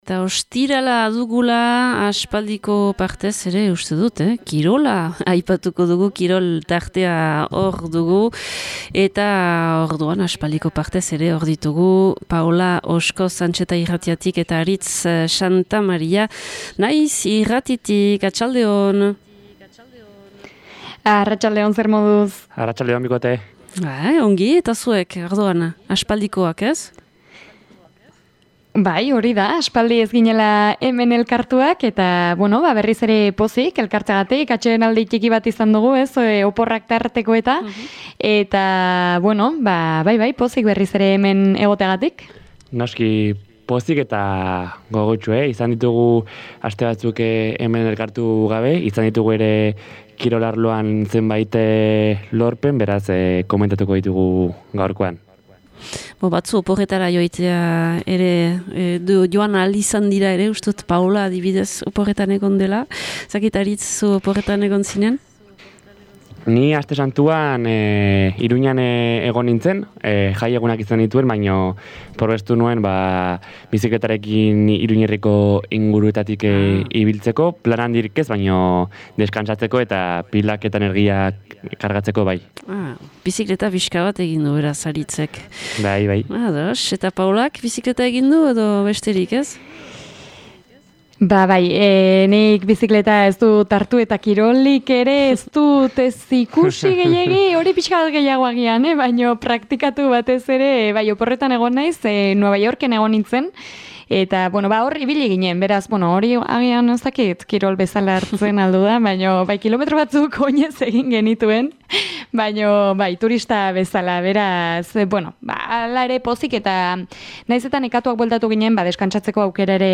Ostiralero legez, gaur Zebrabidean, Kirol Tertulia.